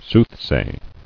[sooth·say]